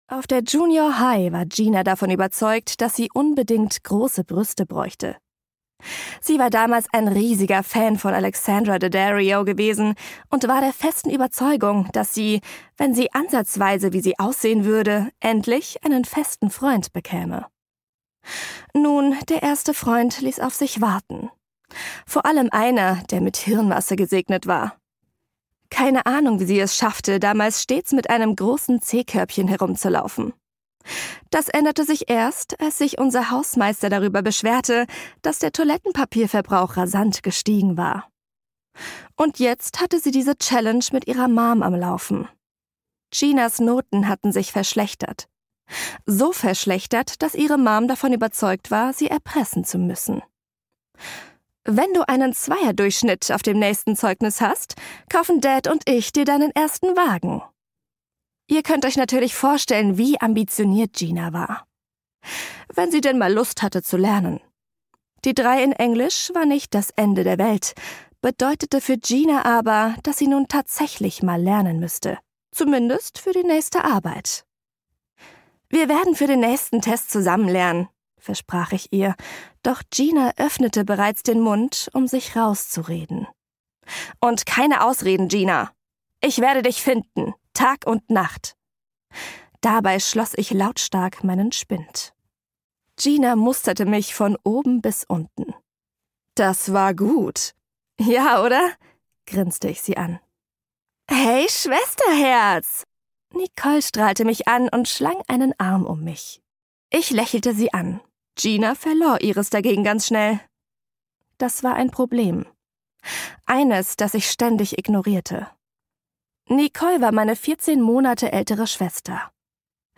Hörbuch - Texas Hope